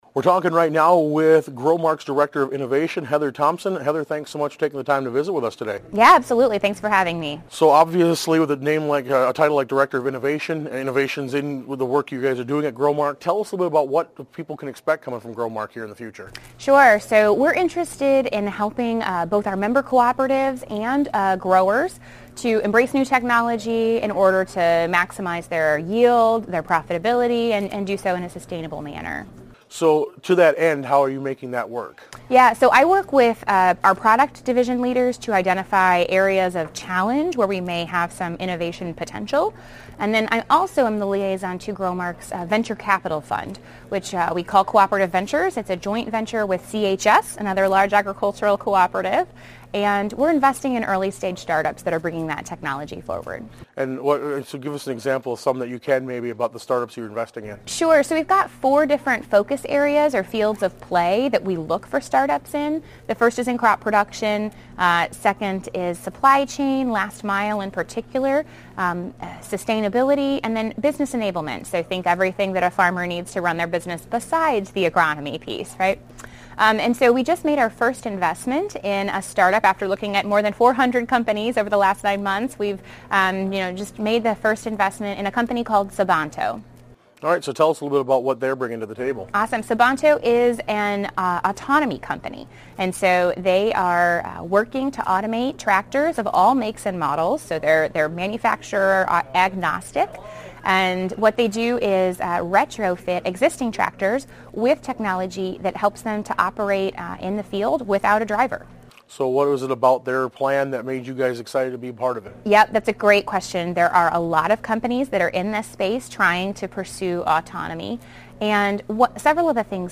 AUDIO: Full interview